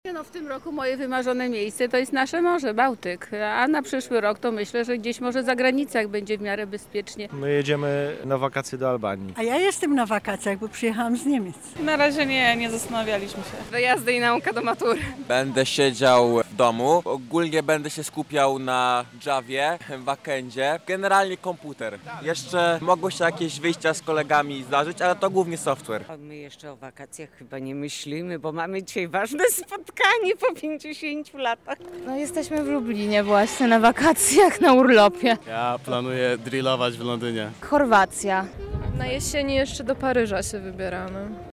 Zapytaliśmy mieszkańców Lublina, jak planują spędzić najcieplejsze miesiące w roku.
sonda